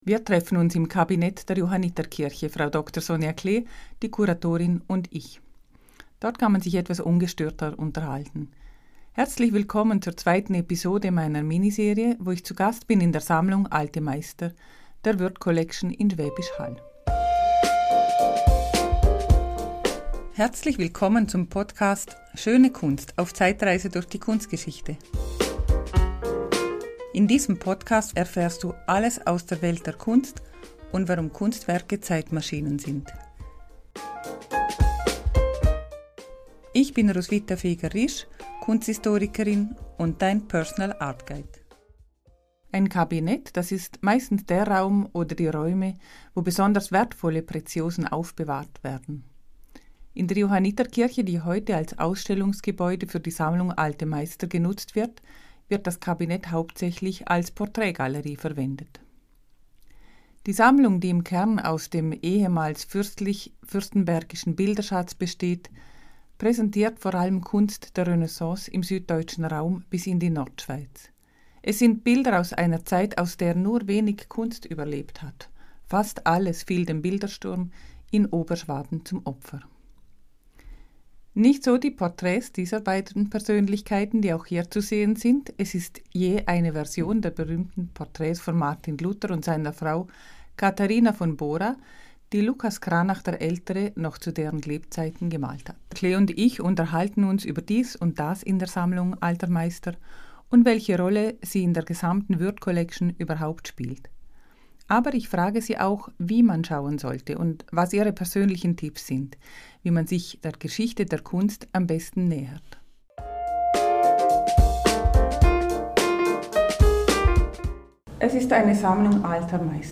In dieser Episode bin ich wieder zu Gast bei der Sammlung Alte Meister der Würth Collection in Schwäbisch Hall.